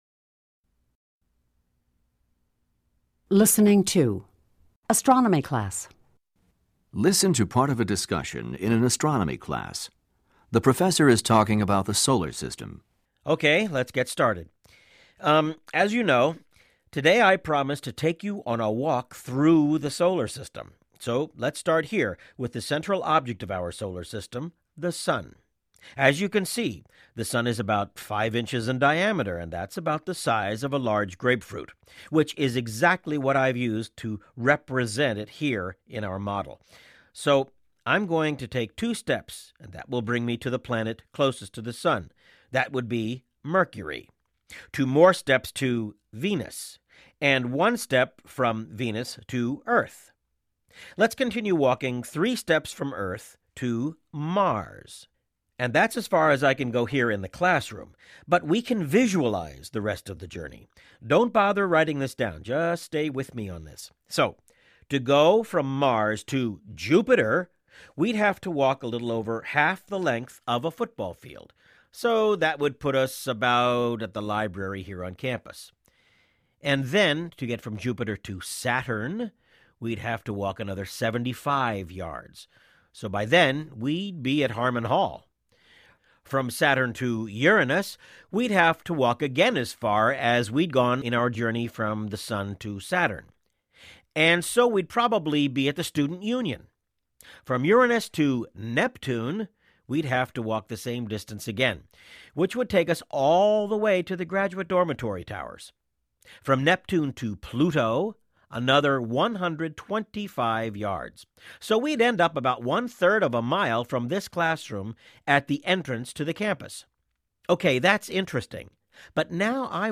Listening 2 "Astronomy Class"